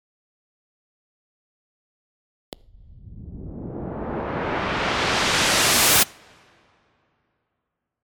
cubase付属音源で作る難しい理屈を省いたシューーーという上昇音（sweep）。
シューーーーーってなりましたね！！！